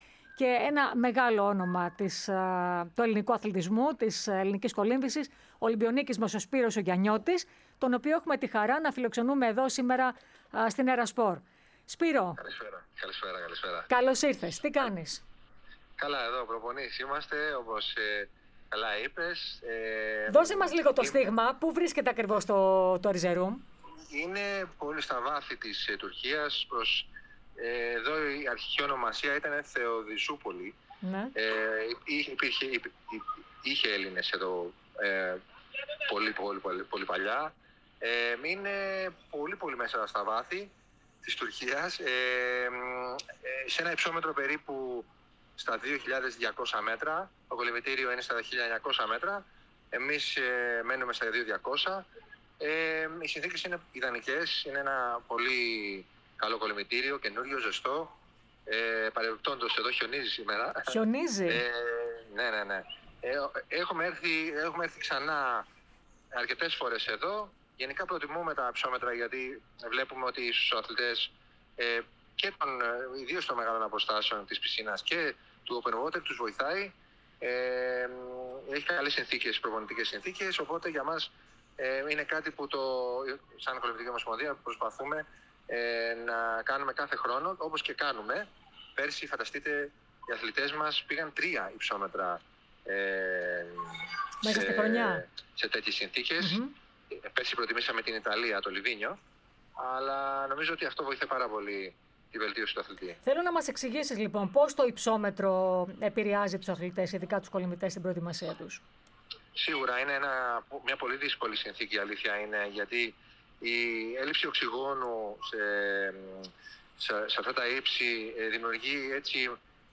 Ο θρυλικός Έλληνας κολυμβητής παραχώρησε συνέντευξη στην κορυφαία αθλητική ραδιοφωνική συχνότητα της χώρας. Αναφέρθηκε στην προετοιμασία που κάνει ως προπονητής πλέον στους Έλληνες κολυμβητές στο Ερζερούμ της Τουρκίας. Επίσης, σχολίασε και το επίπεδο στο οποίο βρίσκεται η νέα γενιά, αλλά και τις πιθανότητες που έχουν για σημαντικές επιτυχίες στο μέλλον. Τέλος, σχολίασε και τις αποφάσεις της Διεθνούς Ολυμπιακής Επιτροπής, ενόψει των Ολυμπιακών Αγώνων του Λος Άντζελες το 2028.